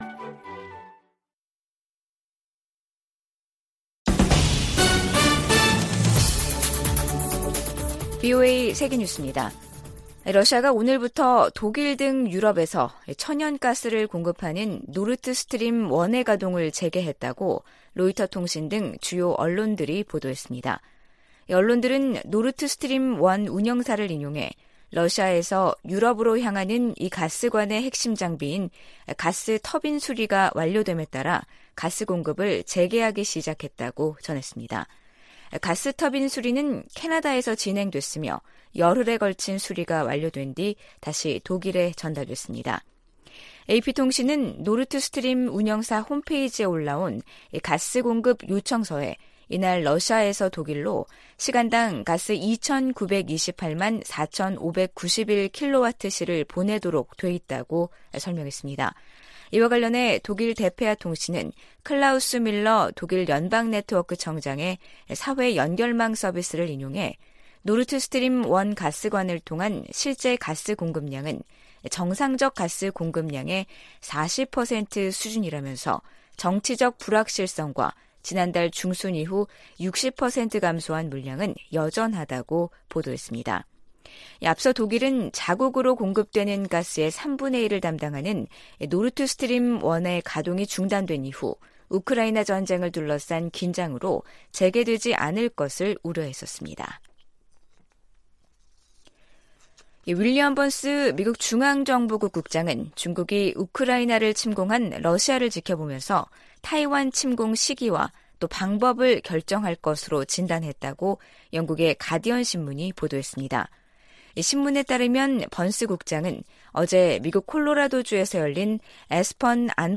VOA 한국어 간판 뉴스 프로그램 '뉴스 투데이', 2022년 7월 21일 2부 방송입니다. 북한은 사이버 활동으로 수익을 추구하는 범죄조직이며, 이를 차단하는 것이 미국의 최우선 과제라고 백악관 고위 관리가 밝혔습니다. 백악관 국가안보회의(NSC) 측은 일본의 역내 안보 활동 확대가 북한 등 위협에 대응하는 데 큰 도움이 될 것이라고 말했습니다. 유럽연합(EU)은 북한이 우크라이나 내 친러시아 공화국들을 승인한 데 대해 국제법 위반이라고 비판했습니다.